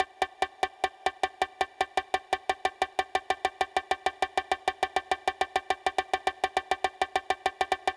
Ensuite je prends le clip midi et je le mets sur 16 niveaux.
Sur 16 niveau c'est moins "humain".
velocity-16-levels.wav